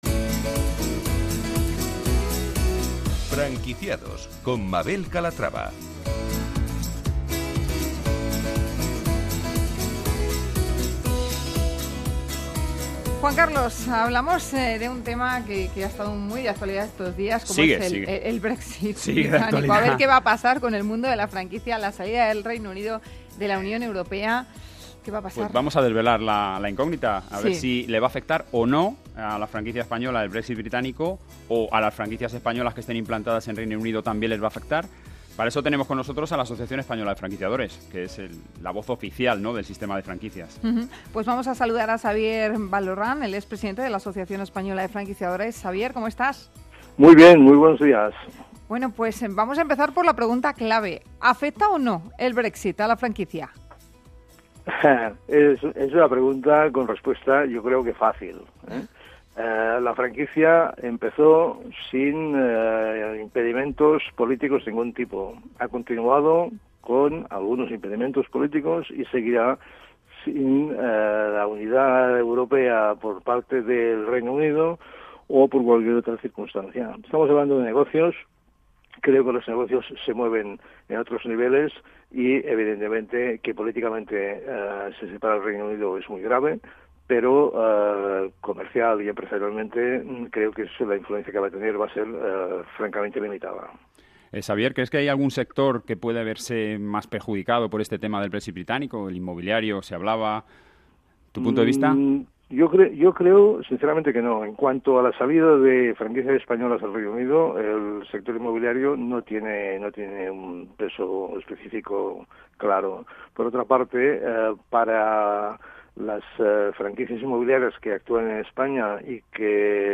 Franquicia2 es el único programa de radio especializado en la industria de la franquicia.